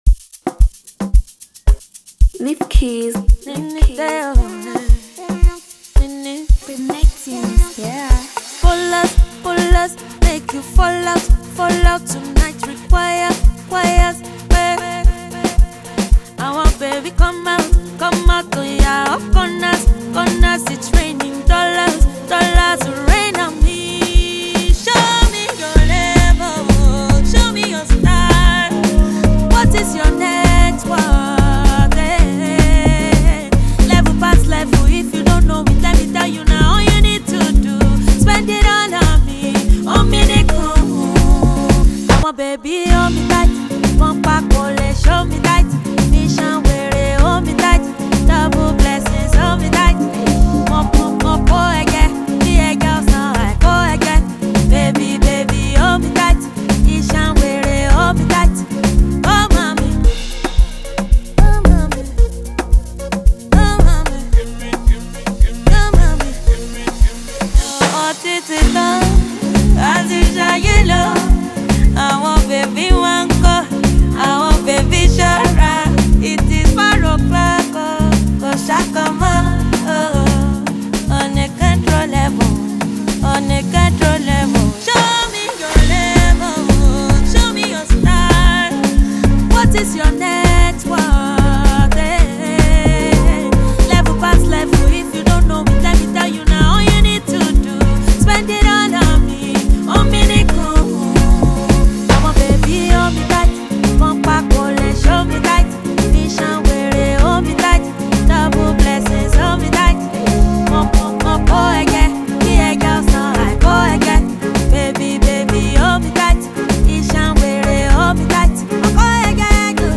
Foreign MusicNaija Music